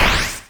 snd_spearrise_ch1.wav